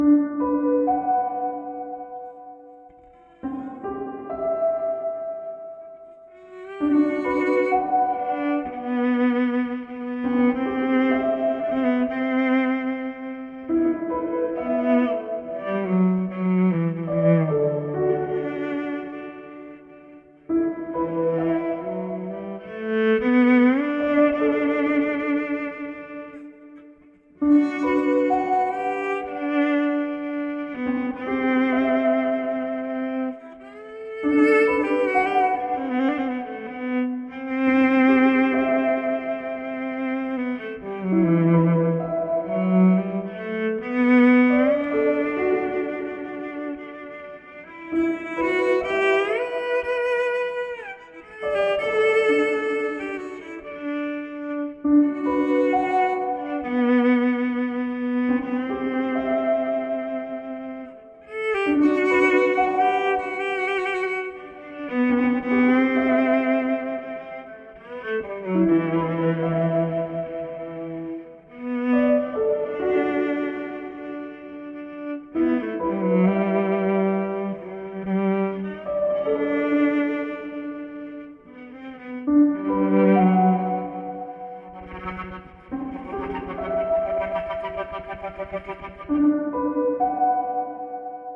Violin = Yamah YEV 104 with Octave strings (plays and octave lower)
AltiSpace IR Vervb - very large airy setting Over to Enso….for a little 8 bar piano loop
I pluck (pizz) the strings on the violin
Faux Cello Channel
FabFilter Pro R - Medium Reverb
Yeah, yeah….. I had a few notes out of tune there on the fiddle…..I couldn’t hear the “piano” part so well, and I was afraid I was out of rhythm.